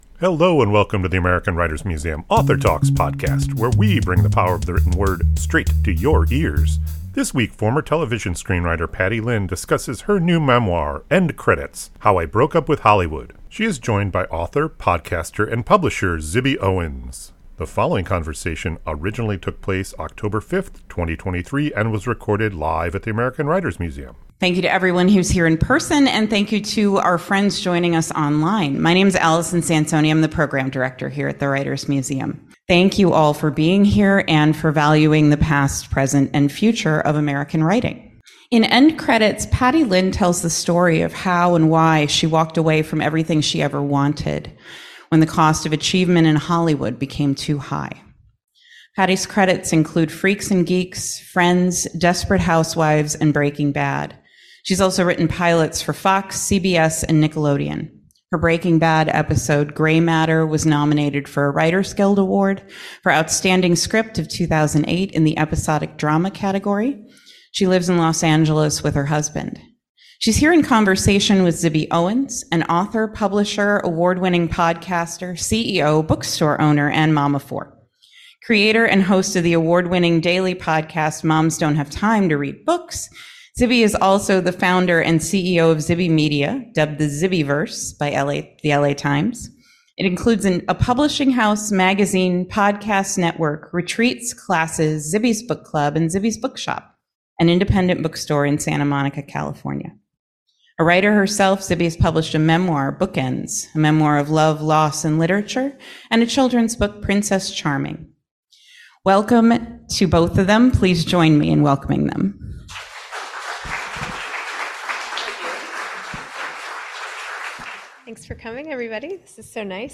This conversation originally took place October 5, 2023 and was recorded live at the American Writers Museum.